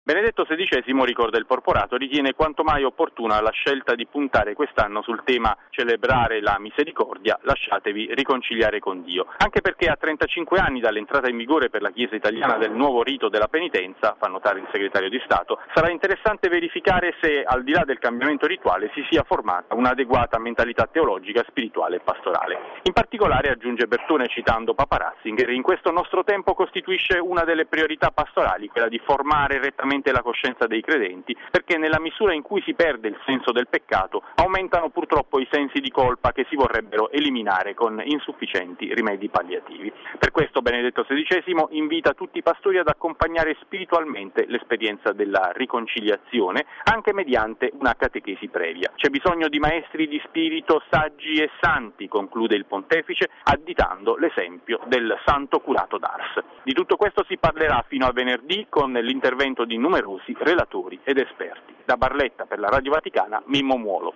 Il Papa auspica che la Settimana Liturgica "contribuisca a favorire una ripresa e un rinnovamento nella celebrazione della Misericordia e nell’esperienza significativa del Perdono divino". Il servizio